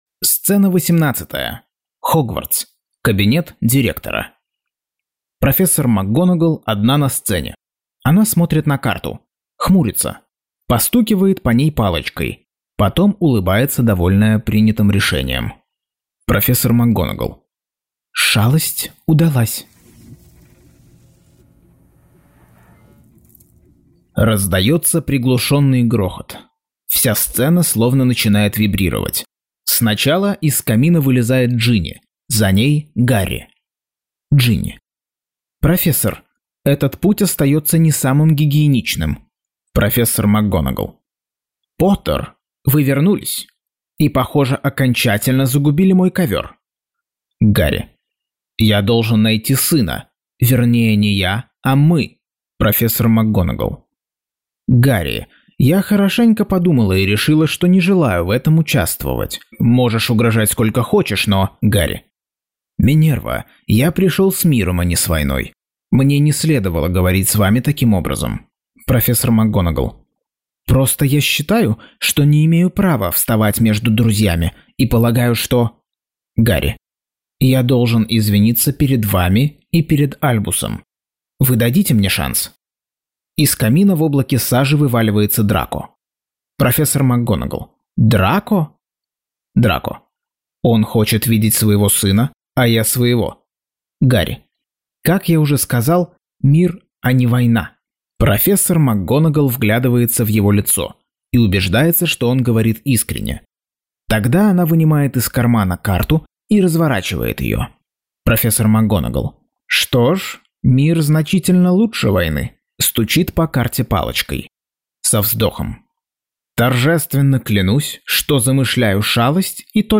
Аудиокнига Гарри Поттер и проклятое дитя. Часть 30.